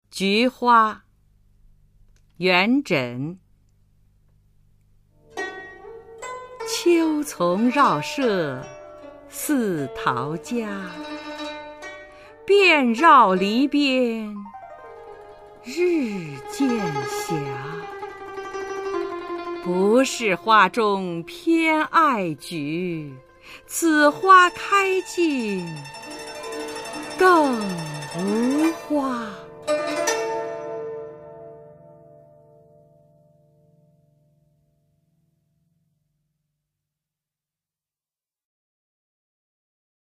[隋唐诗词诵读]元稹-菊花（秋丛绕舍似陶家） 配乐诗朗诵